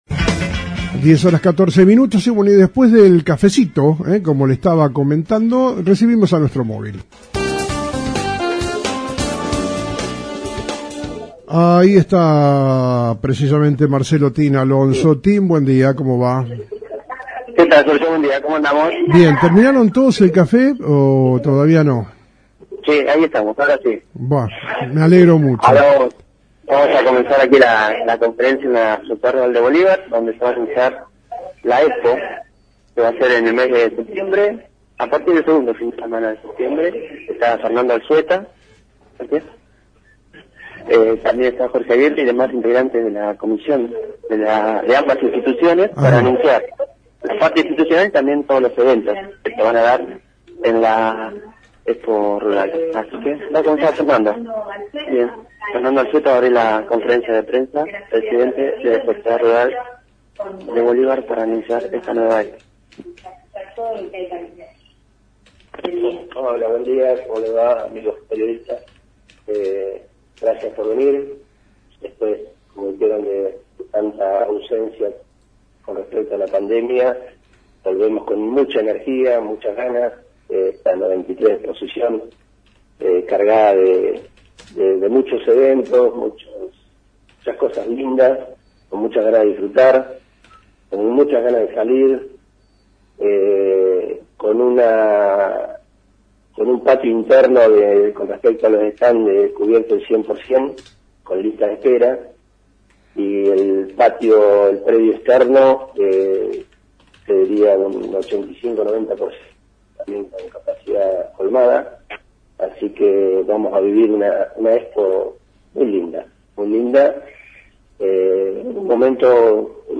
Autoridades de la Sociedad Rural y de la Cámara Comercial